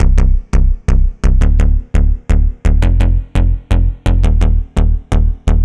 Index of /musicradar/80s-heat-samples/85bpm
AM_OB-Bass_85-C.wav